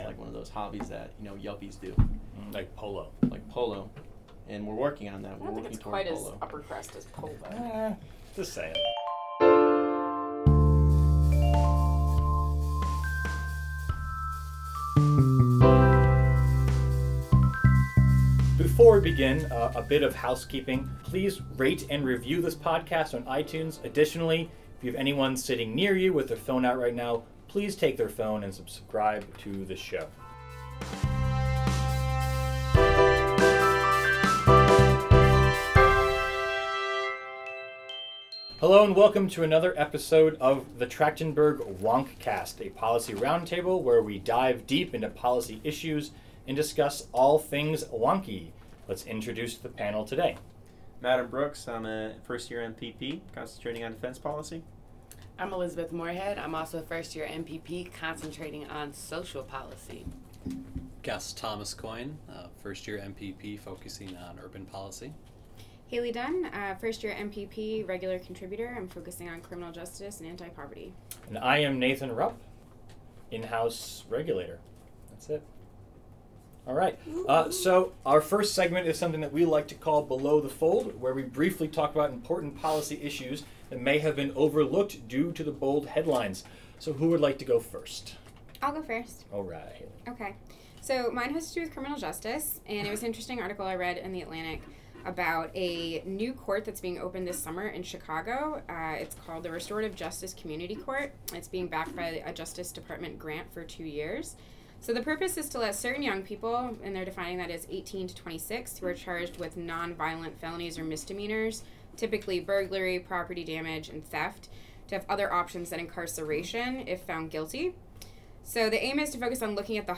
roundtable discussion